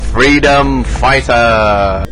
Speech - Freedom fighter!